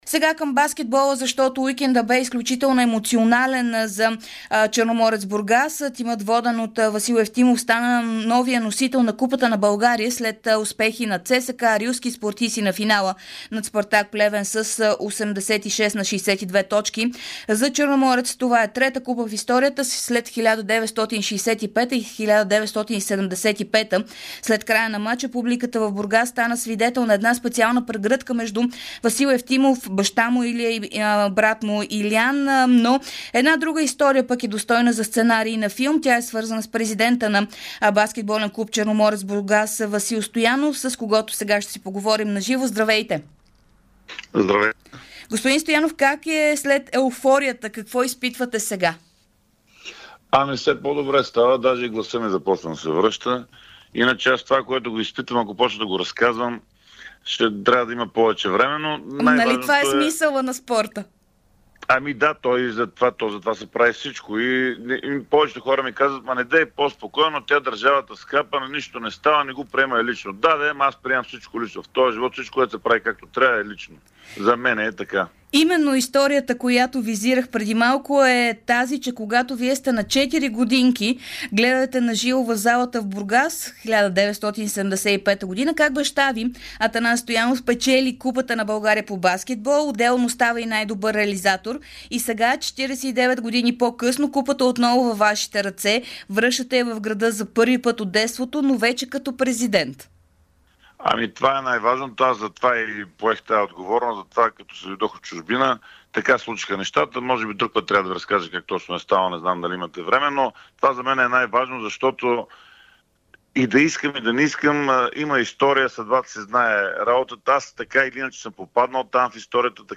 специално интервю